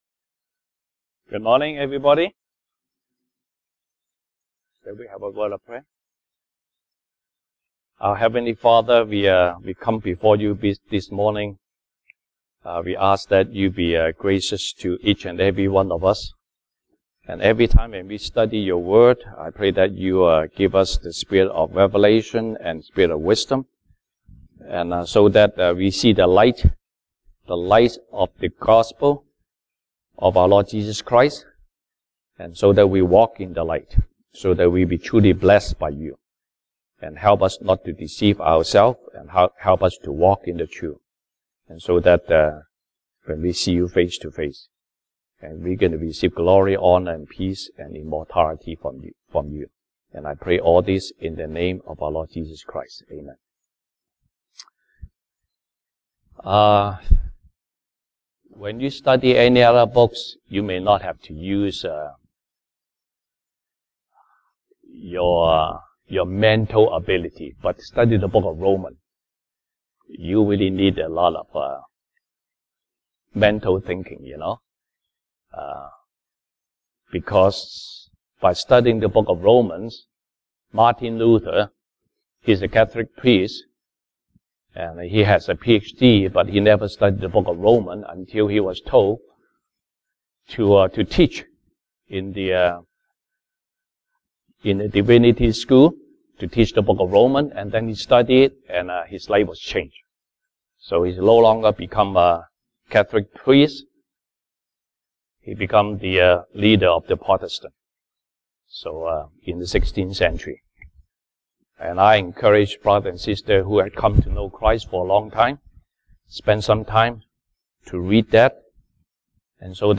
Sunday Service English Topics